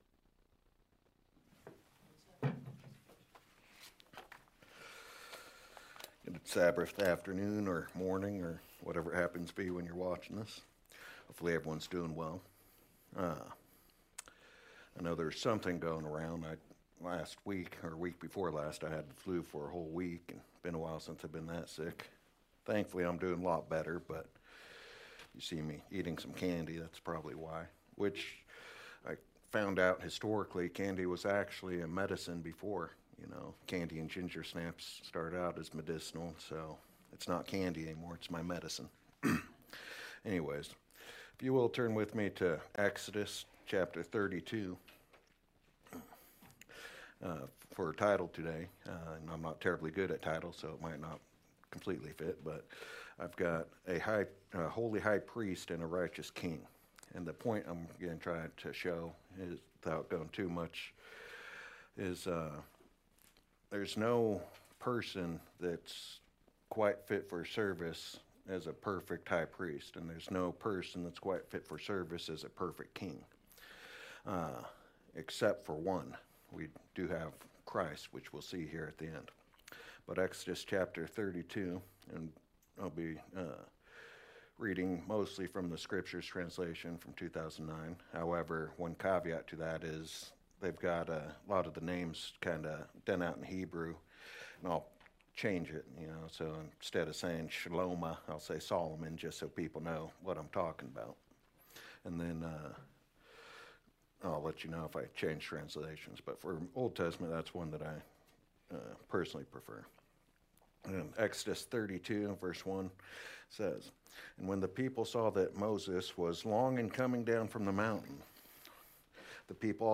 New Sermon | PacificCoG
From Location: "Kennewick, WA"